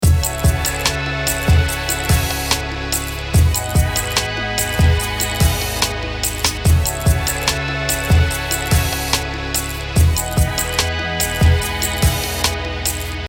楽曲（HipHop的な使い方）
通常時